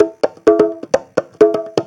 Conga Loop 128 BPM (17).wav